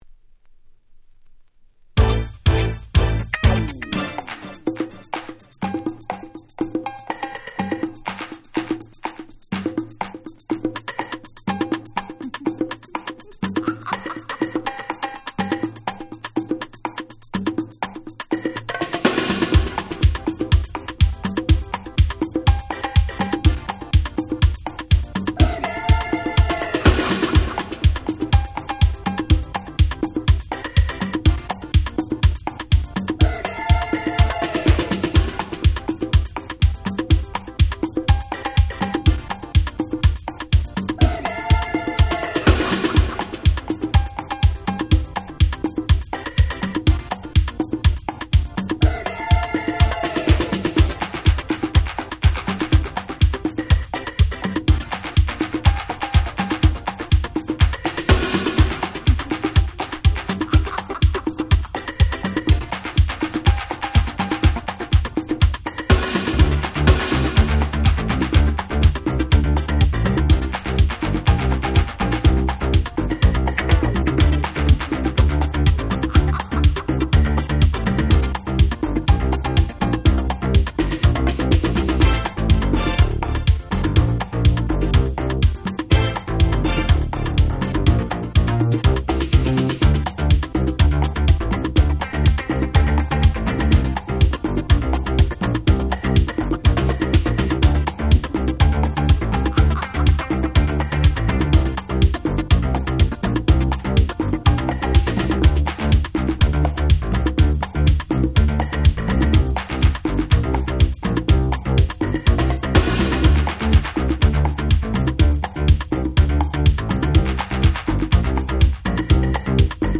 88' Very Nice Cover Disco !!
原曲に忠実ながらもさらにフロア向けにアレンジされた好カバー！！
彼女のエモーショナルな歌声も最高！！
Disco ディスコ